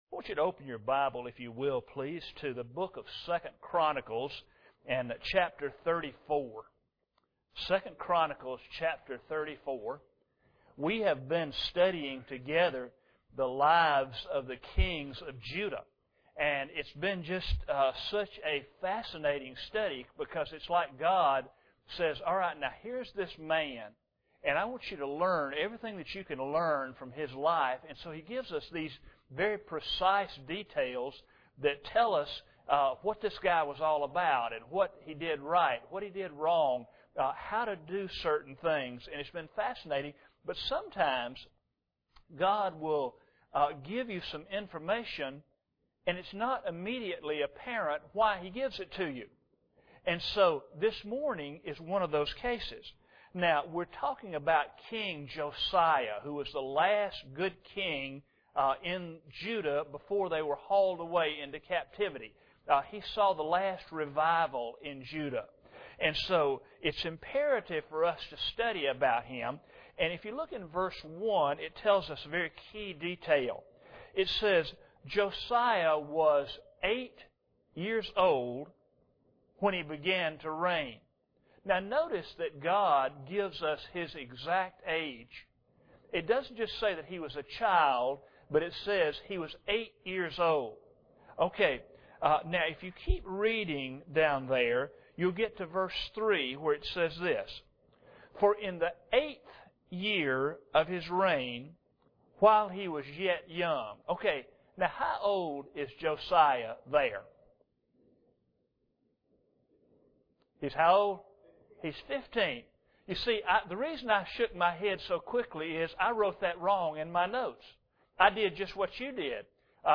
This sermon follows the first years of King Josiah’s reign.
Service Type: Sunday Morning